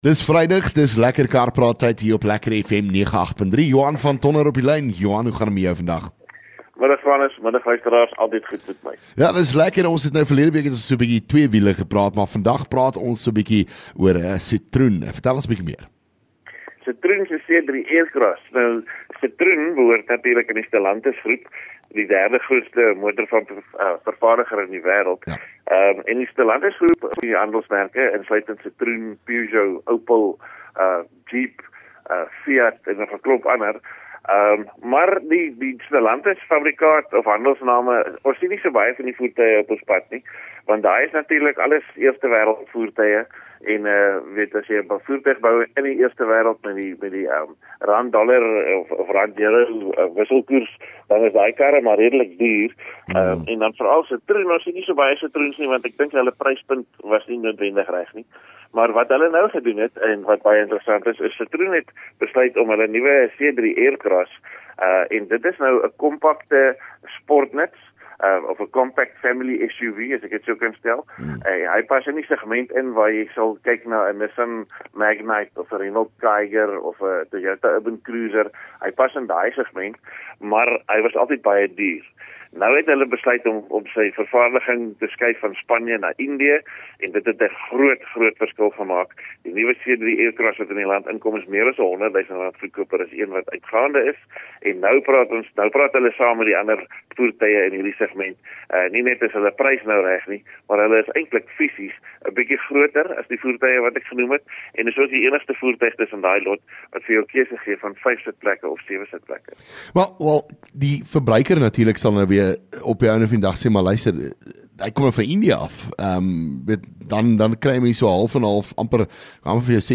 LEKKER FM | Onderhoude 18 Oct LEKKER Kar Praat